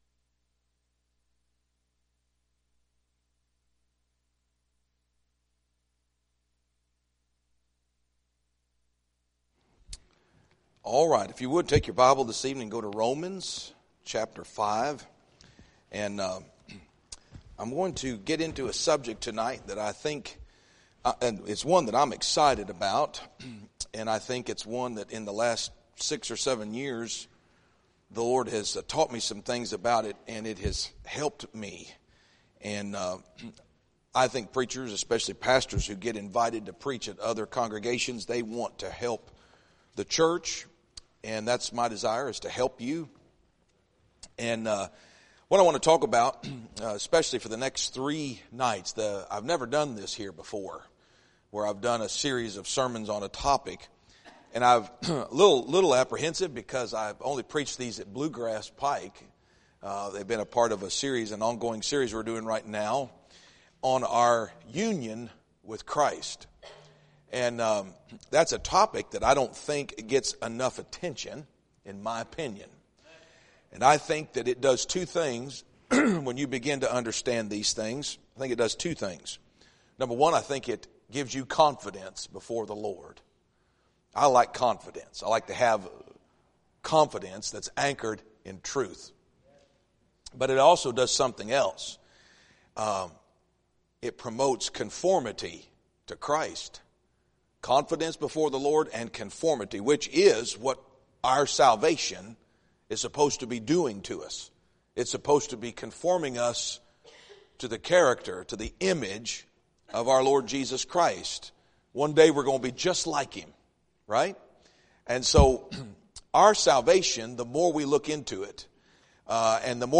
2026 Revival Meeting